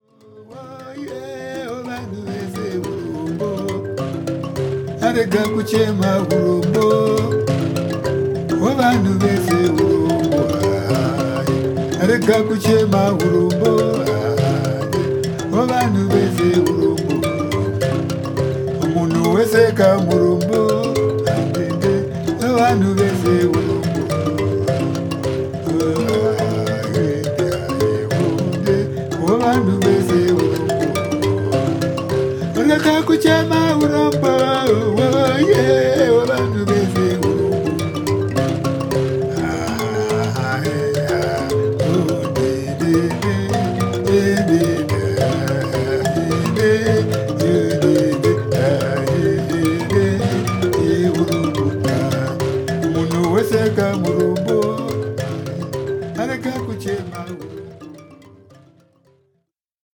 フォーマット：LP